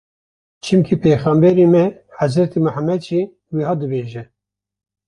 Pronúnciase como (IPA)
/t͡ʃɪmˈkiː/